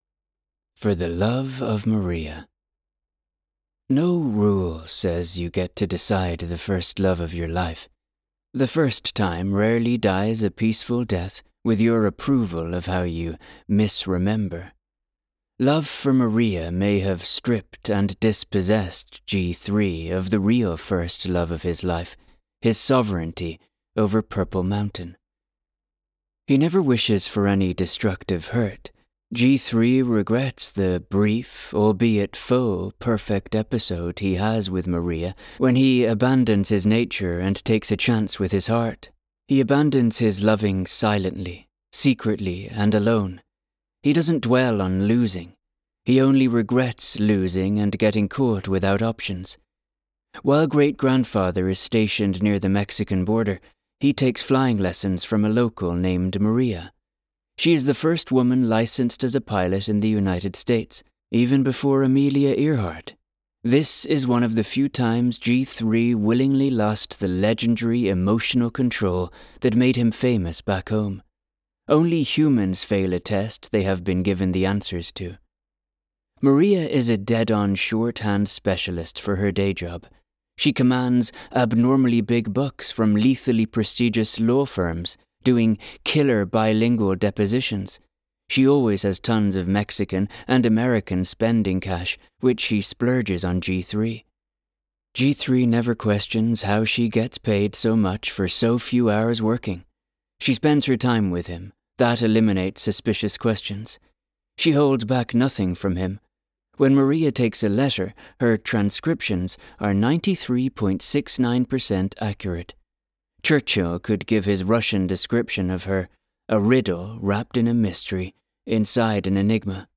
Audio Book Preview